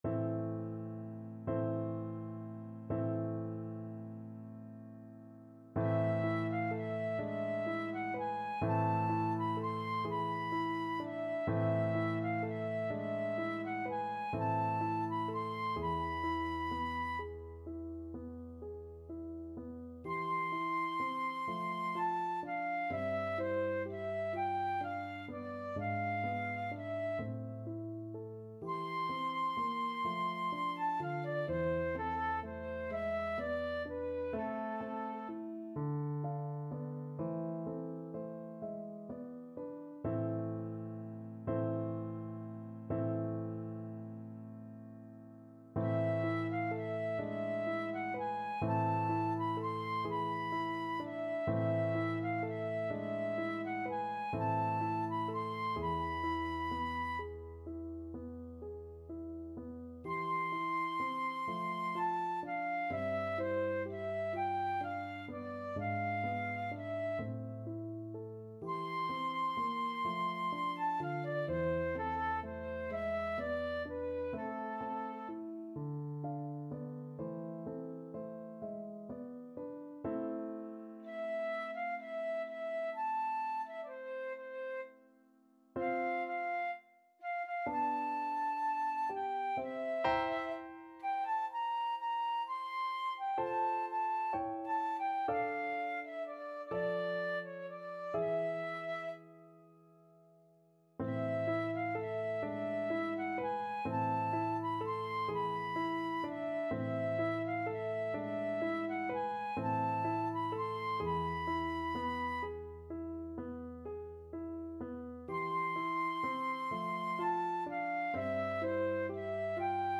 Classical Schubert, Franz Der Abend, D.108 Flute version
Flute
6/8 (View more 6/8 Music)
A minor (Sounding Pitch) (View more A minor Music for Flute )
= 42 Andante con moto (View more music marked Andante con moto)
Classical (View more Classical Flute Music)